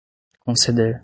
Prononcé comme (IPA)
/kõ.seˈde(ʁ)/